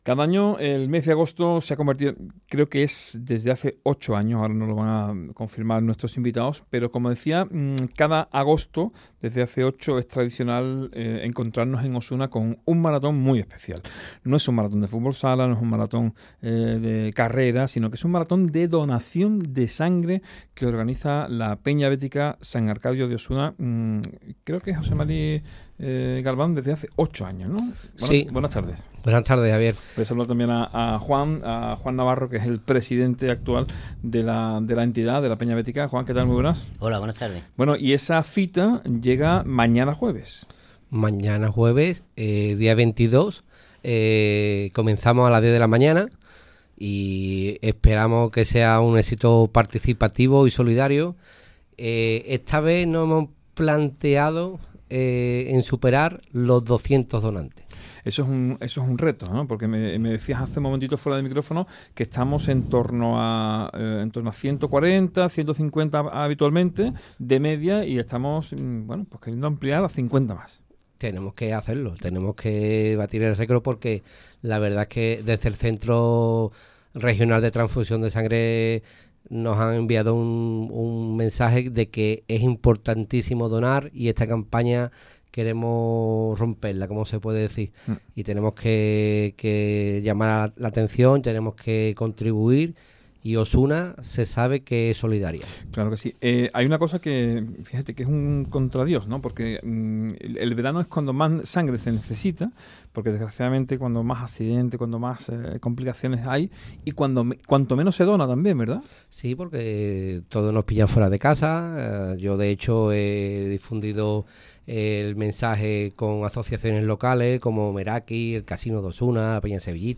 ENTREVISTA | Maratón de donación de sangre en Osuna - Andalucía Centro